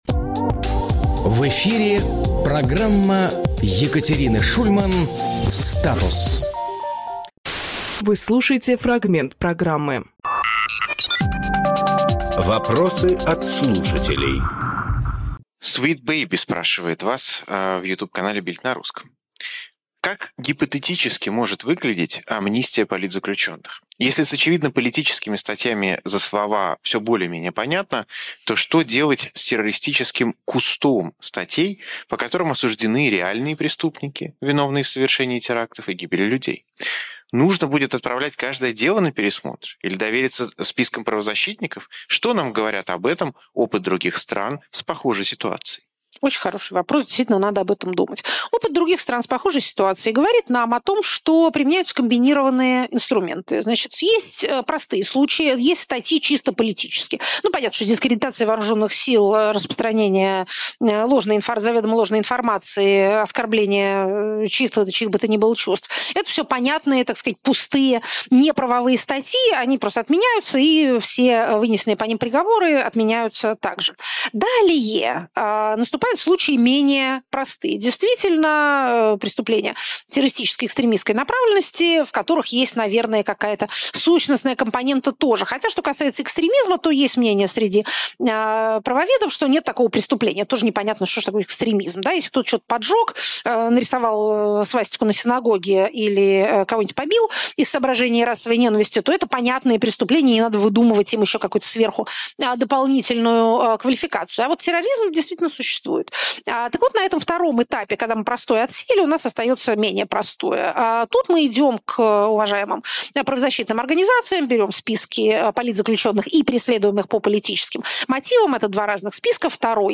Екатерина Шульманполитолог
Фрагмент эфира от 10.02.26